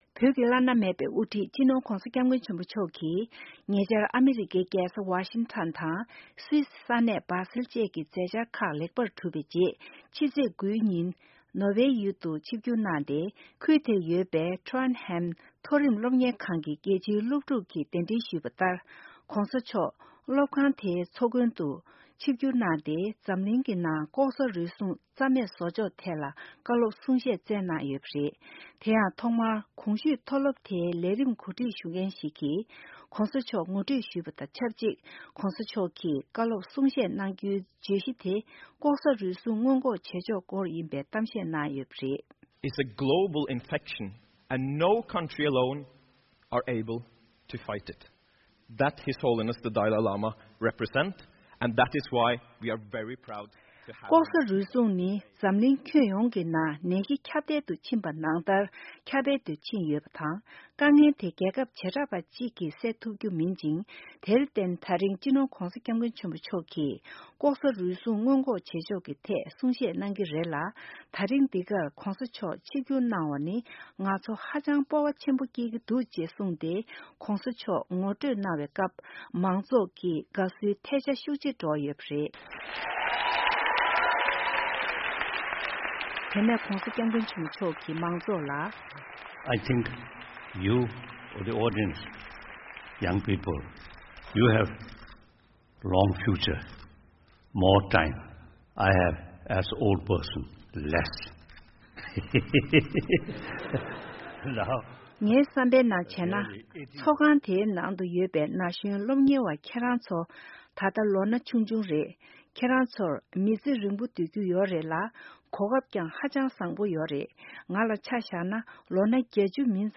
༧གོང་ས་མཆོག་གིས་ནོ་ཝེའི་ཡུལ་དུ་མཐོ་སློབ་ཞིག་ལ་གསུང་བཤད་གནང་ཡོད་པ།